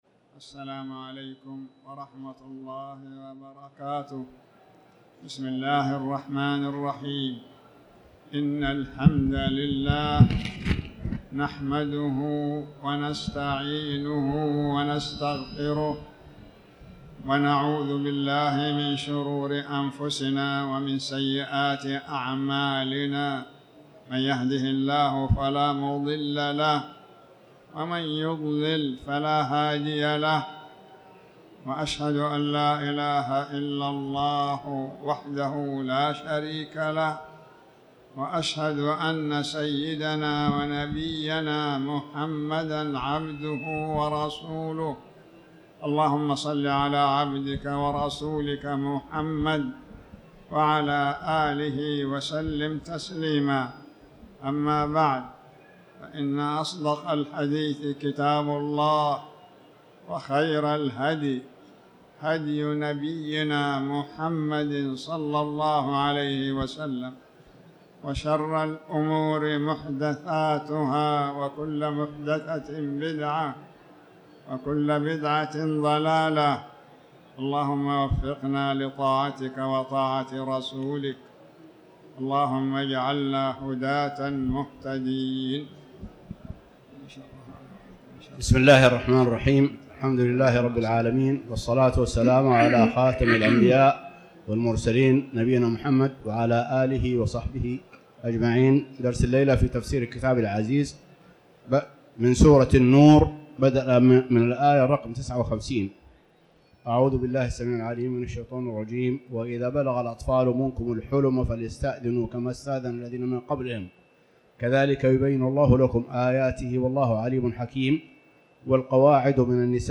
تاريخ النشر ١ جمادى الآخرة ١٤٤٠ هـ المكان: المسجد الحرام الشيخ